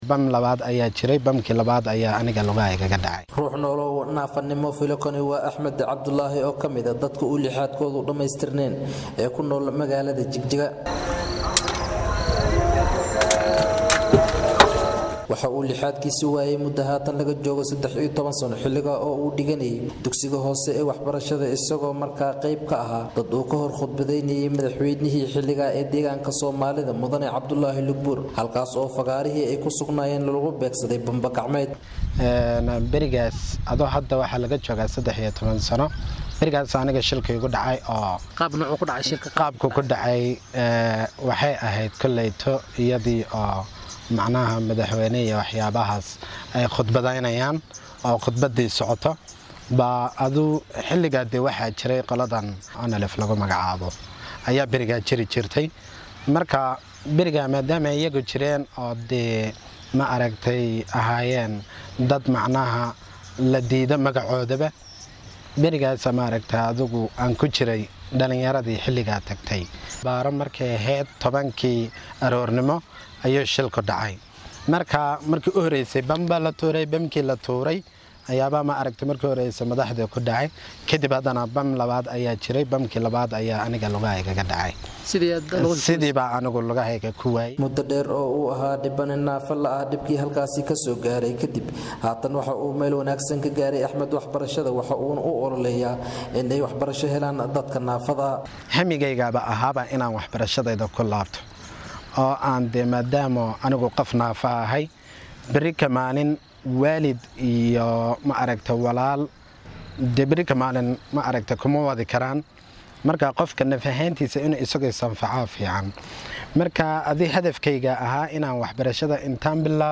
DHAGEYSO:Warbixin: Wiil u ololeeya waxbarashada dadka naafada ah Jigjiga